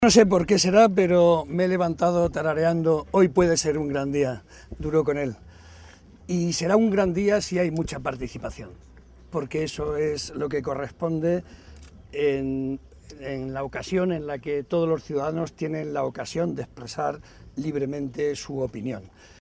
Barreda, que hizo estas declaraciones en Ciudad Real, tras asistir a depositar su voto, señaló que “hoy puede ser un gran día si hay mucha participación” pues, en su opinión, “eso es lo que corresponde al día de hoy”.